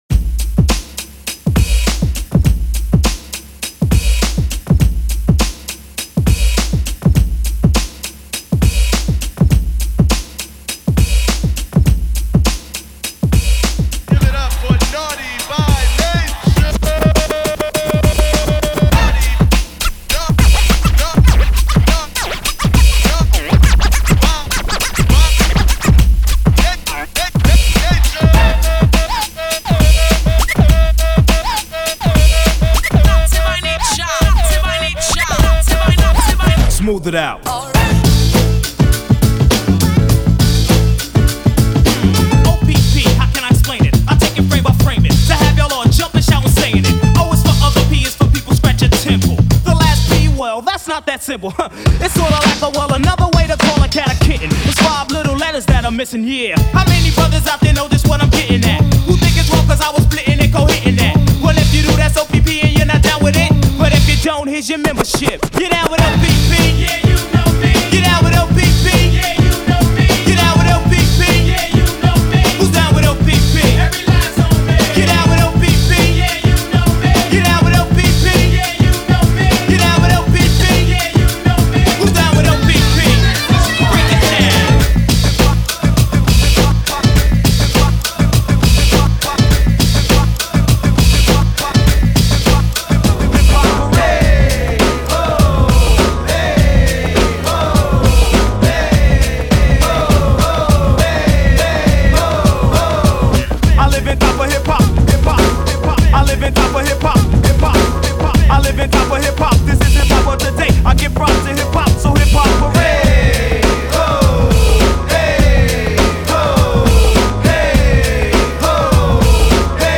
Bring the Classic Hip-Hop Energy to Miami Nights
a high-energy remix by Miami’s DJ & VJ